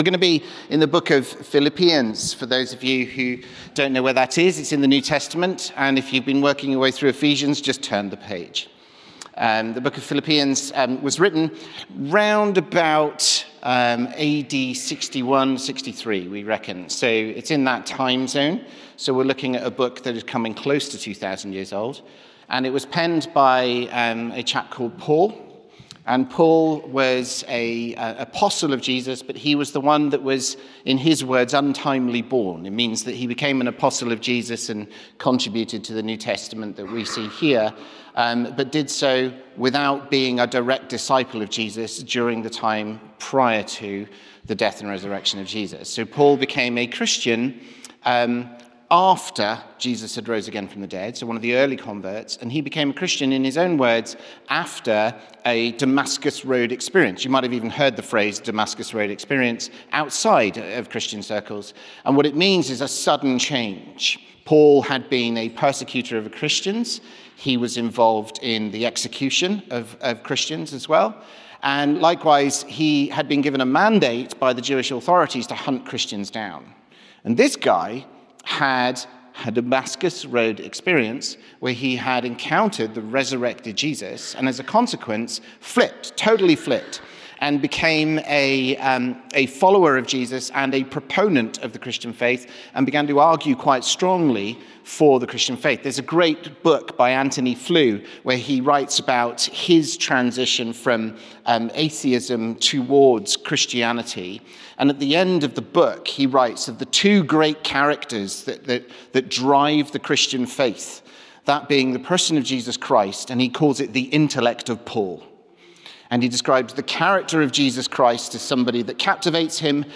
Sermon - Introduction to Philippians 1:1-11